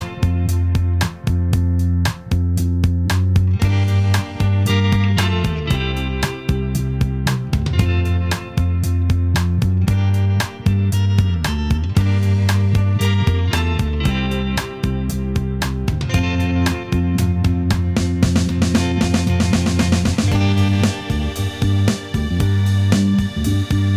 Minus Lead Guitar Pop (1970s) 2:58 Buy £1.50